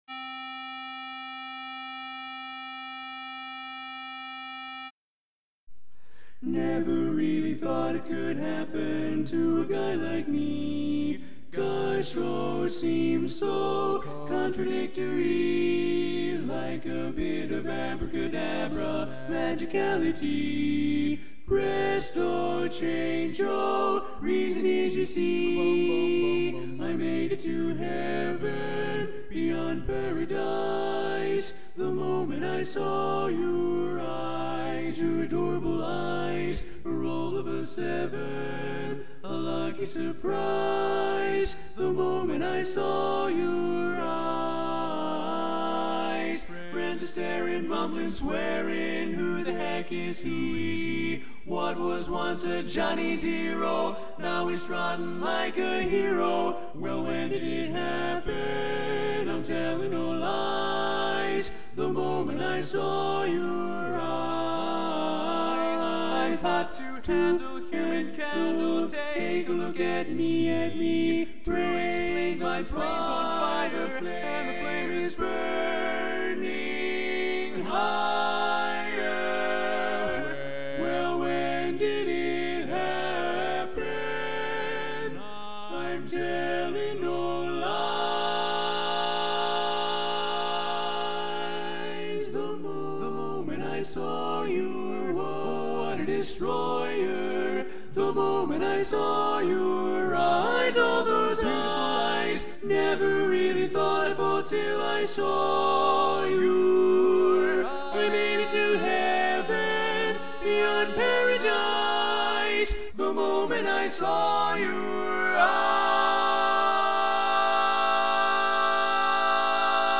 Kanawha Kordsmen (chorus)
Up-tempo
C Major